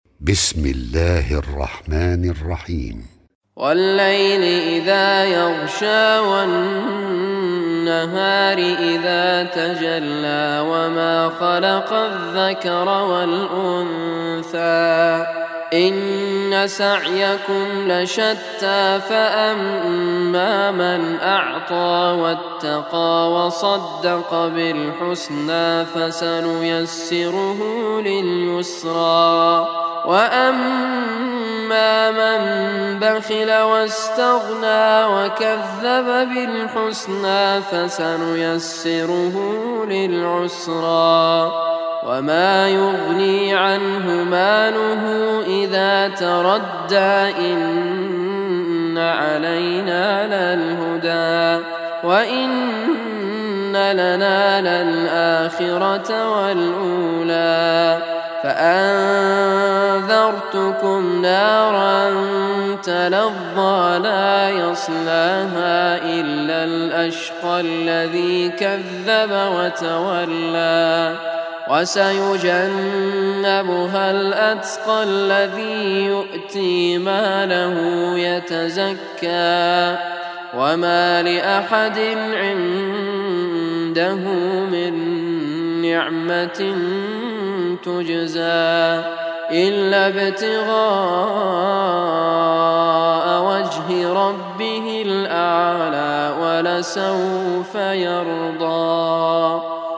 Surah Lail Recitation by Sheikh Raad Al Kurdi
Surah Lail, listen or play online mp3 tilawat / recitation in arabic in the beautiful voice of Sheikh Raad Al Kurdi.